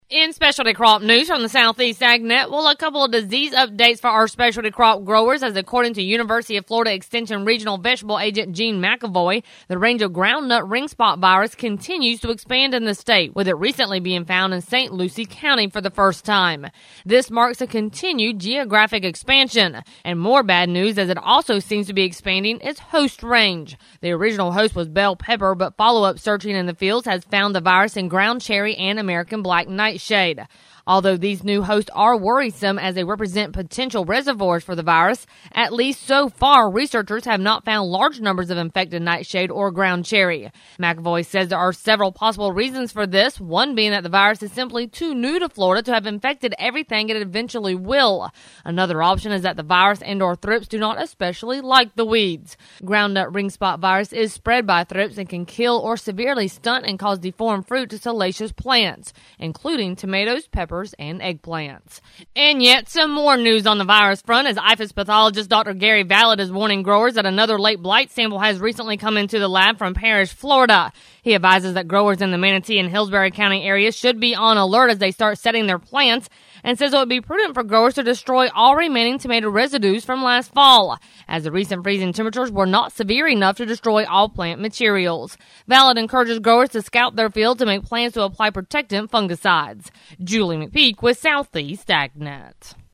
The following report has a couple of disease updates for our specialty crop growers.